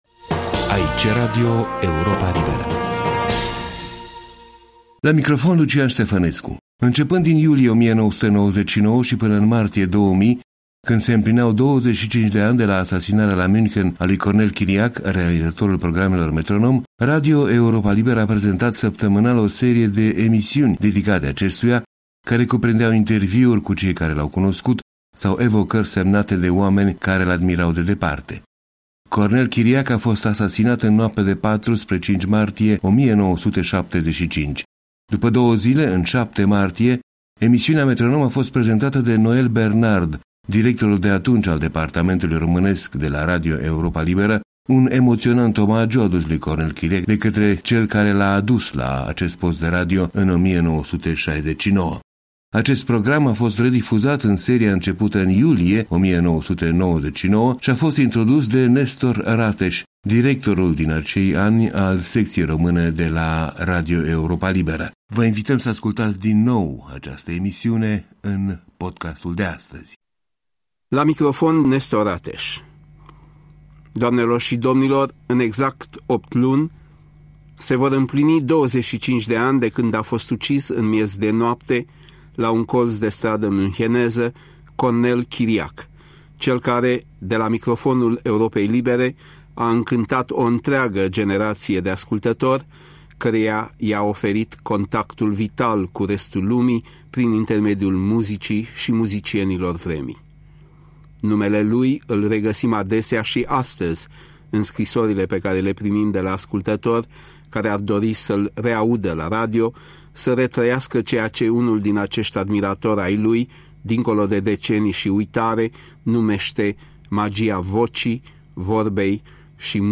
Emisiunea „Metronom” prezentată de directorul Europei Libere după asasinarea lui Cornel Chiriac la München, în 5 martie 1975.